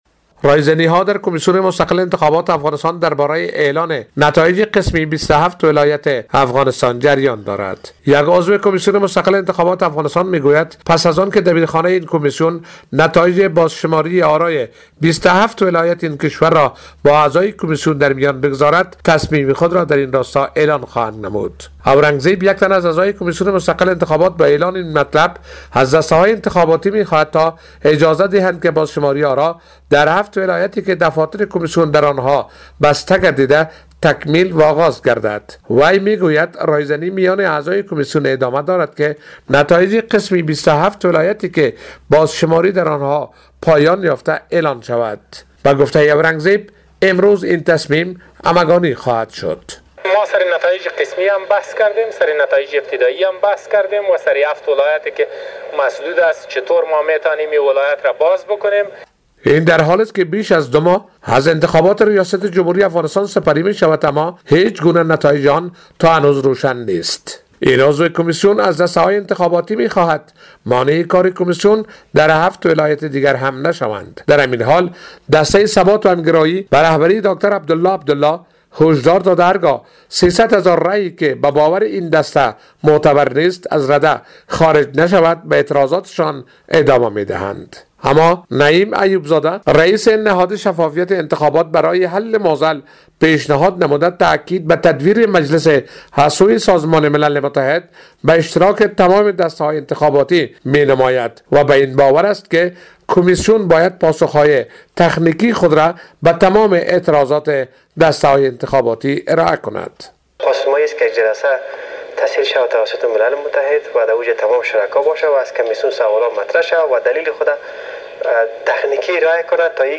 به گزارش خبرنگار رادیو دری،اورنگ زیب عضو کمیسیون مستقل انتخابات افغانستان،از دسته های انتخاباتی خواست اجازه دهند بازشماری آرا در هفت ولایتی که دفاتر کمیسیون در آن ها بسته شده آغاز وتکمیل گردد.